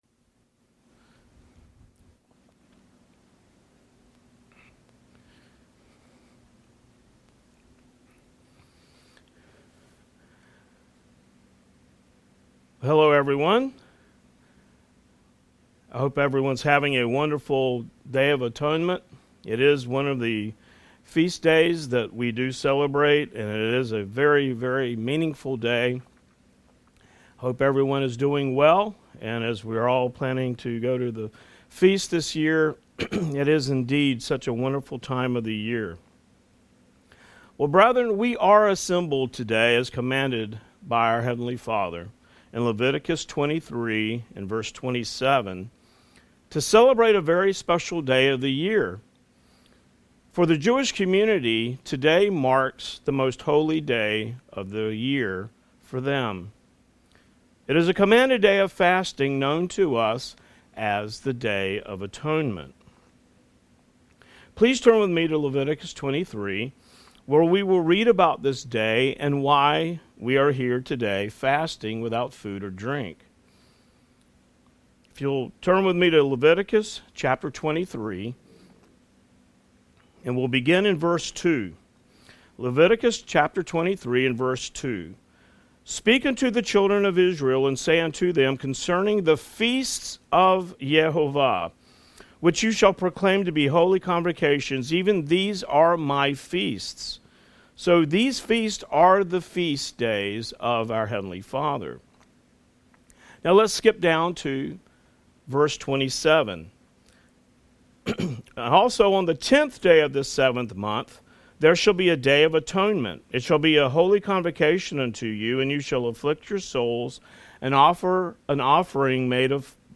New Sermon | PacificCoG
From Location: "Houston, TX"